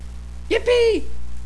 yippee.wav